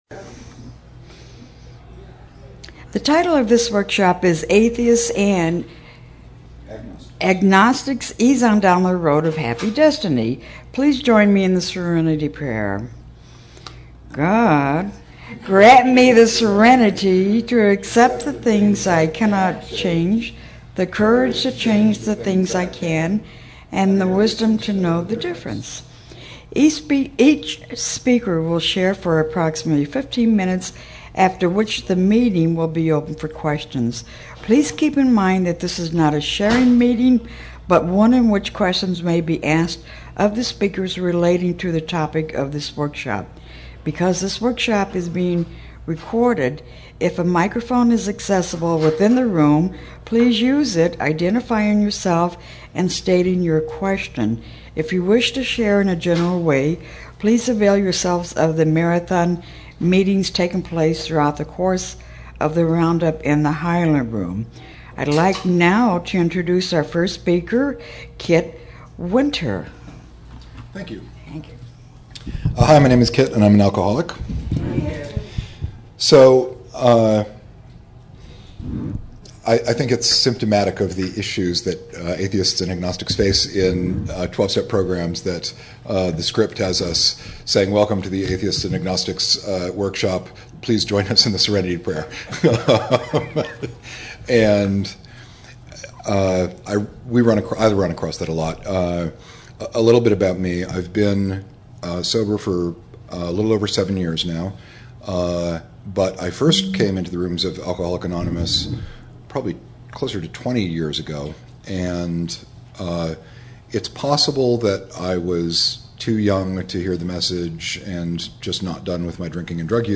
LGBTQ Speakers - Atheists and Agnostics